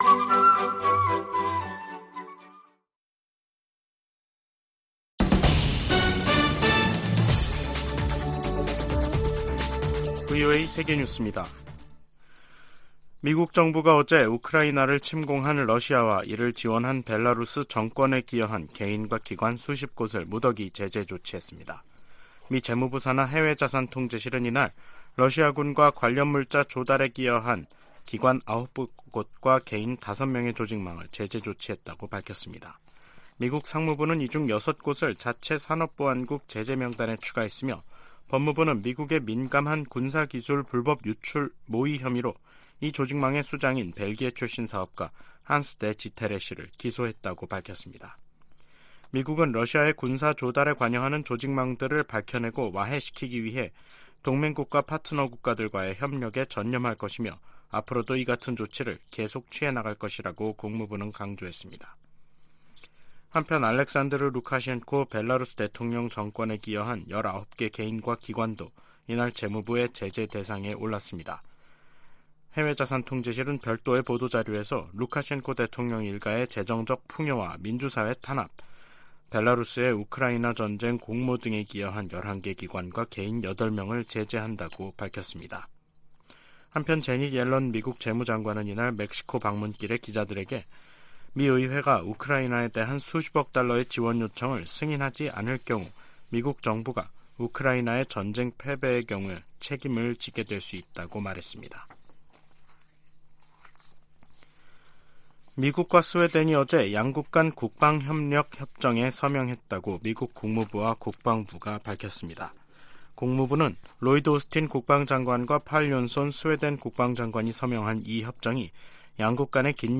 VOA 한국어 간판 뉴스 프로그램 '뉴스 투데이', 2023년 12월 6일 3부 방송입니다. 유엔총회가 북한 핵실험을 규탄하고 핵무기 폐기를 촉구하는 내용을 담은 결의 3건을 채택했습니다. 유럽연합(EU)이 북한과 중국, 러시아 등 전 세계의 심각한 인권 침해 책임자와 기관에 제재를 3년 연장했습니다. 북한 지도자가 딸 주애를 계속 부각하는 것은 세습 의지를 과시하기 위해 서두르고 있다는 방증이라고 한국 고위관리가 지적했습니다.